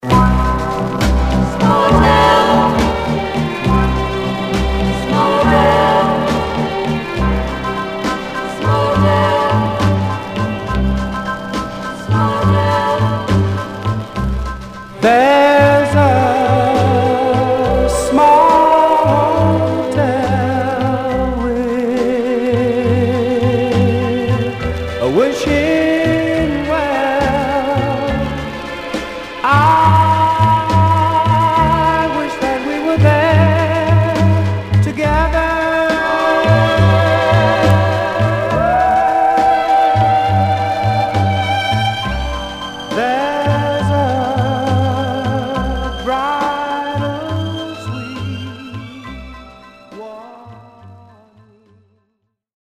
Surface noise/wear
Mono
Teen